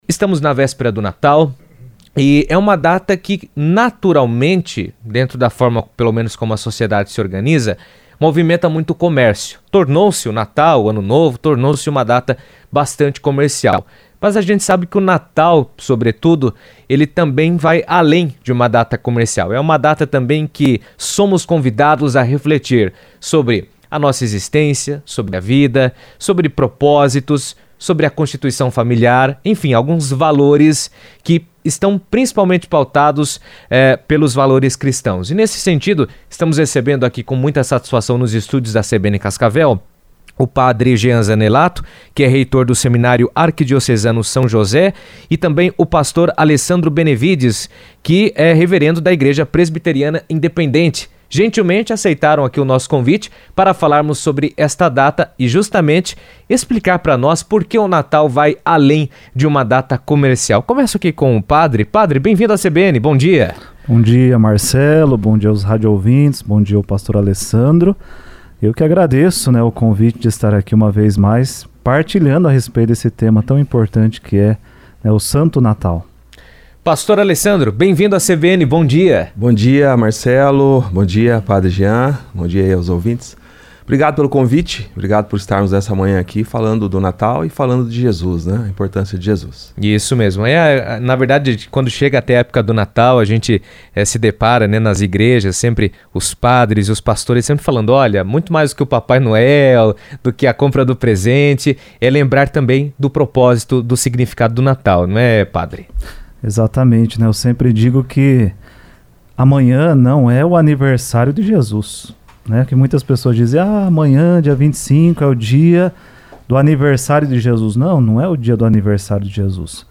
Natal vai além das festas: padre e pastor refletem sobre o verdadeiro sentido da data